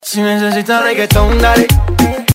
Reggaeton Dale
reggaetondale2.mp3